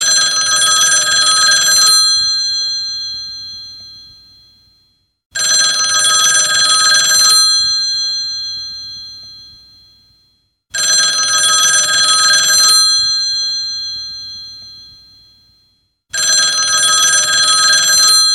Old Telephone klingelton kostenlos
Kategorien: Telefon